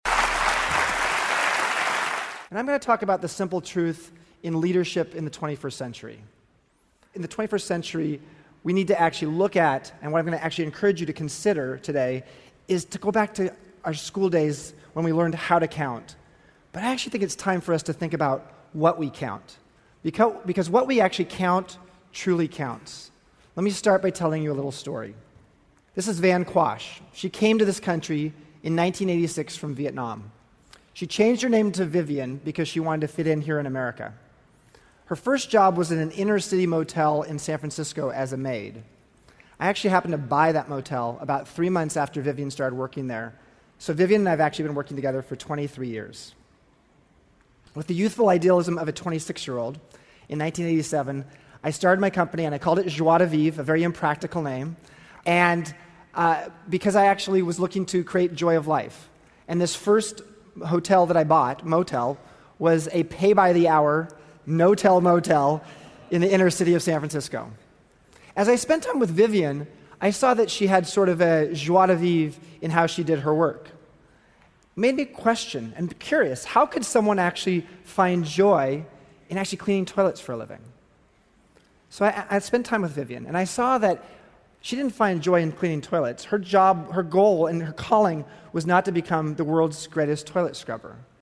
财富精英励志演讲70：计算让生命有意义的事情(1) 听力文件下载—在线英语听力室